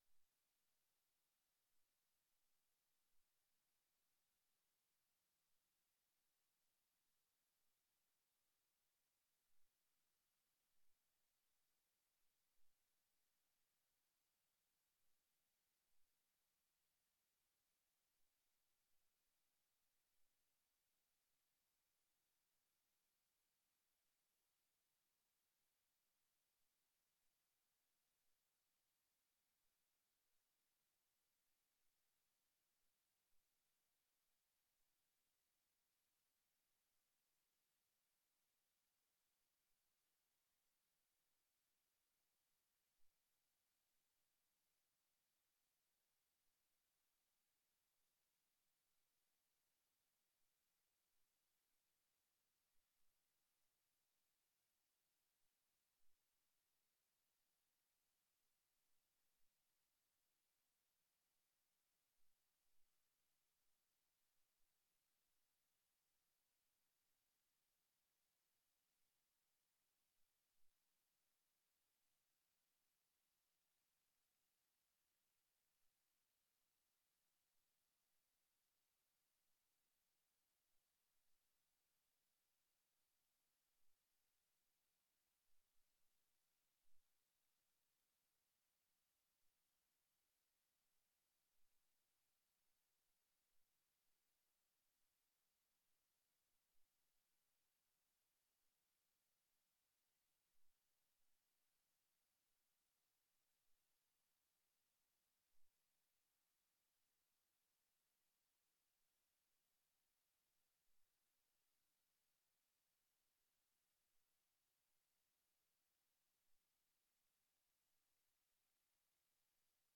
Sermons by Newberg Church of Christ